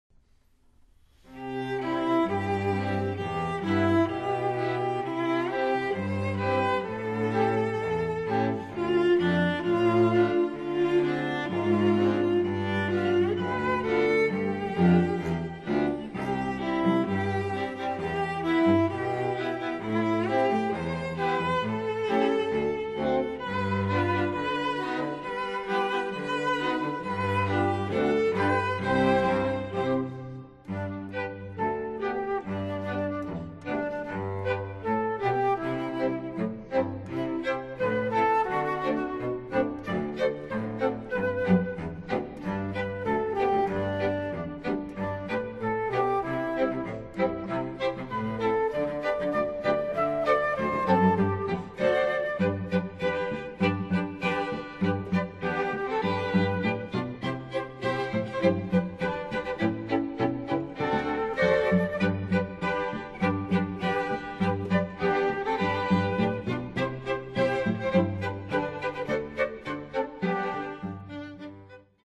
(Flute, Violin, Viola and Cello)
MIDI